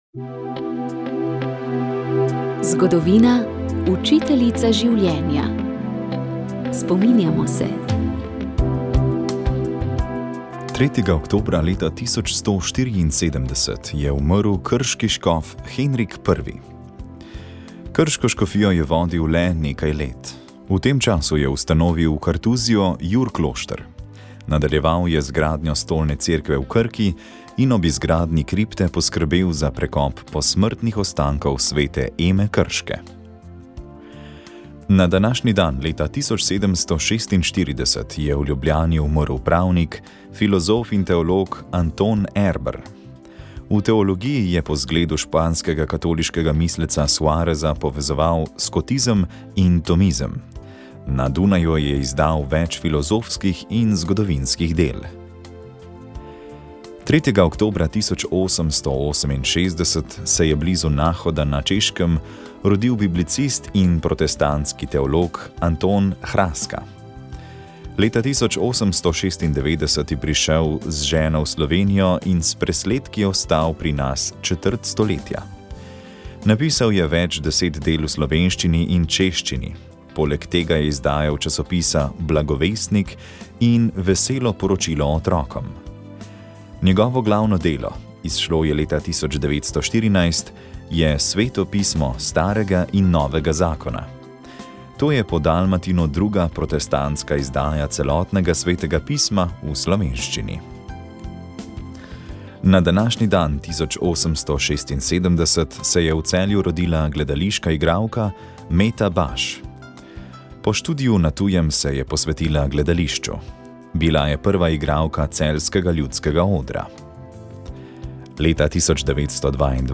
otroci otrok zgodbe zgodba pravljica pravljice slovensko slovenske Zgodbe za otroke